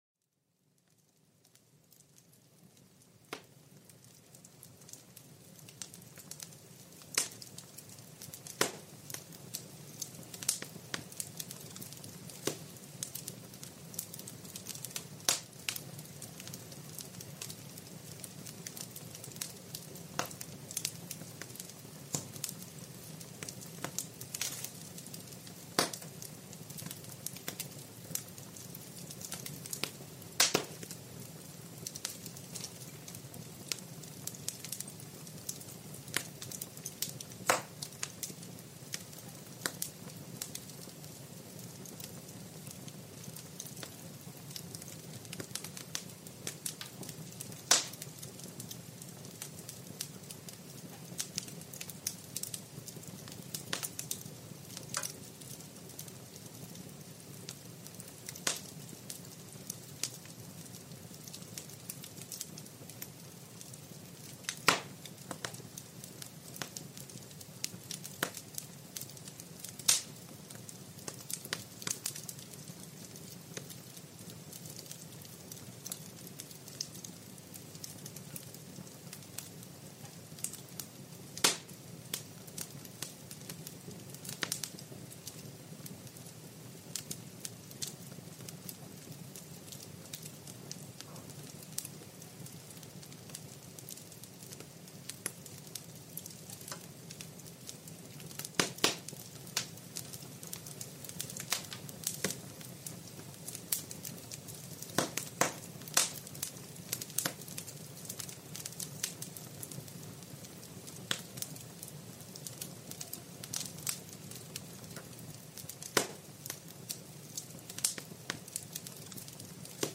Sonidos de fuego de chimenea para una relajación acogedora